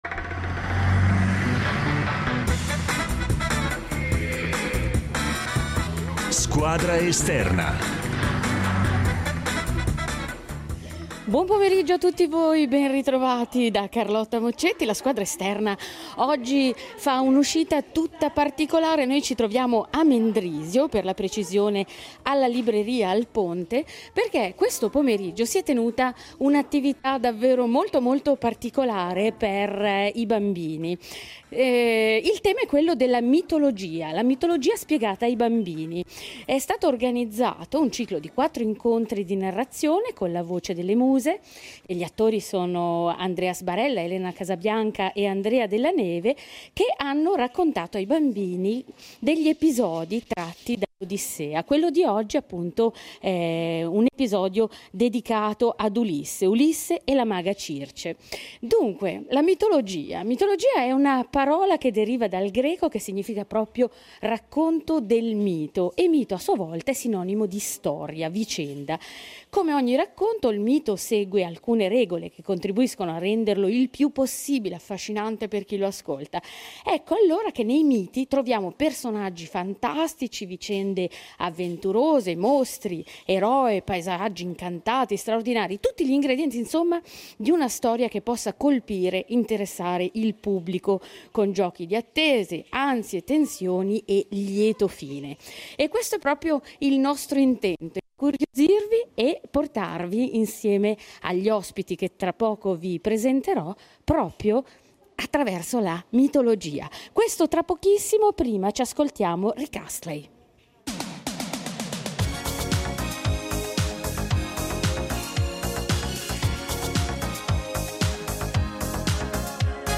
Per l’occasione la SQUADRA ESTERNA di RETE UNO è venuta a “curiosare” dietro le quinte.